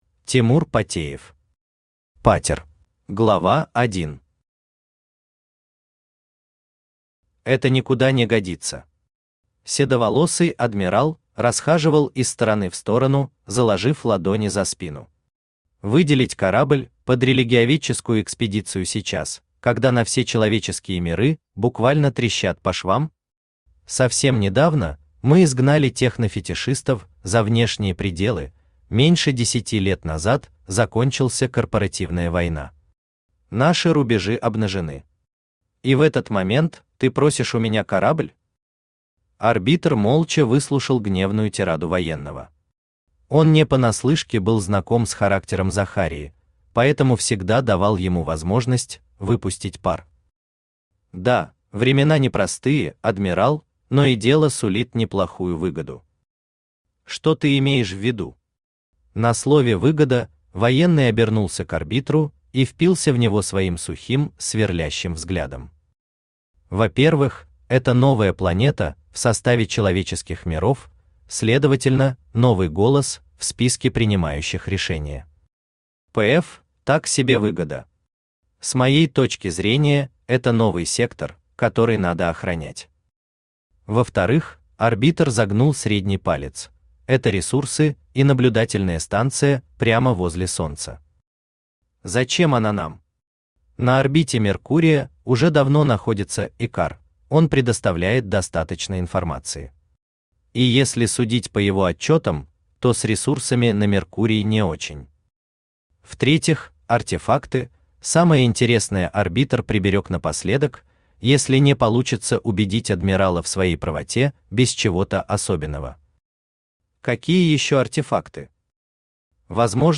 Аудиокнига Патер | Библиотека аудиокниг
Aудиокнига Патер Автор Тимур Камилевич Патеев Читает аудиокнигу Авточтец ЛитРес.